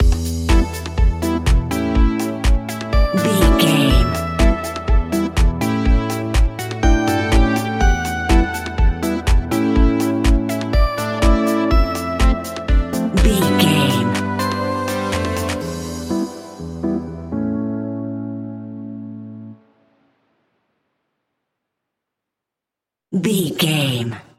Aeolian/Minor
uplifting
energetic
bouncy
synthesiser
drum machine
electric piano
funky house
nu disco
groovy
upbeat
synth bass